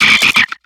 Cri de Pifeuil dans Pokémon X et Y.